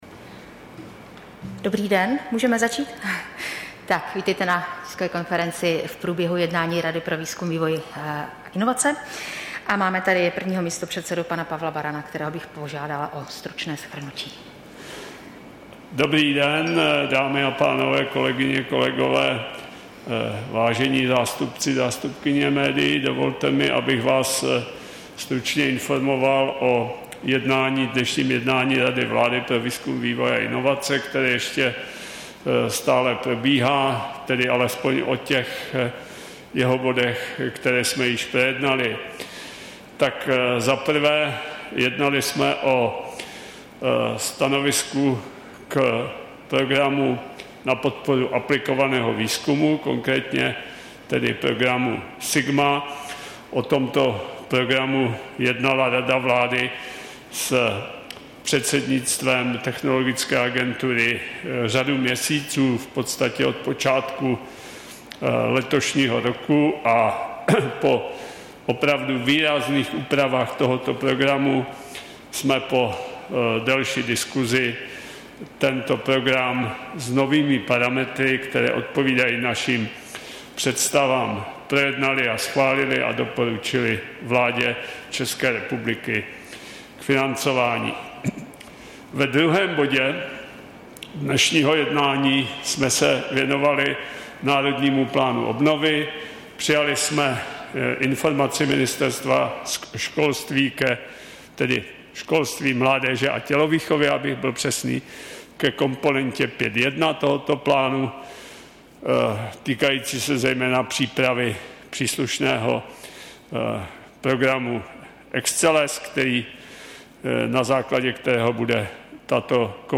Tisková konference po jednání Rady pro výzkum, vývoj a inovace, 26. listopadu 2021